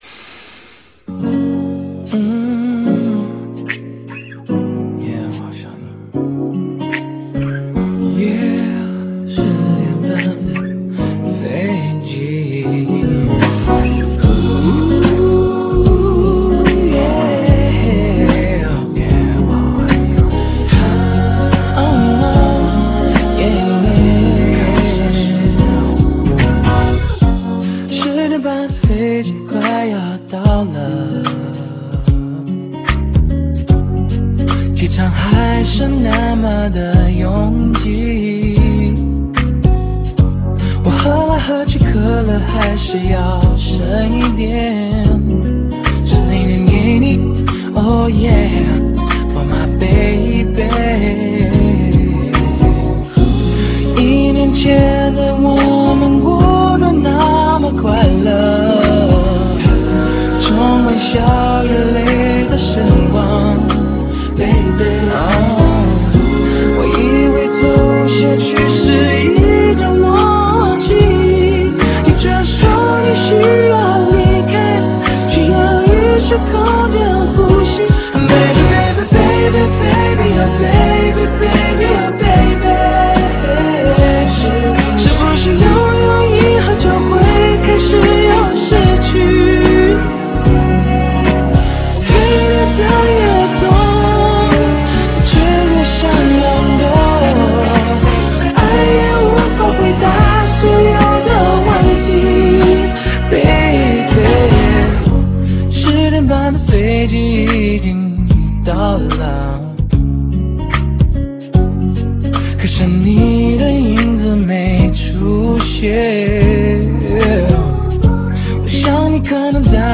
A great song with a nice melody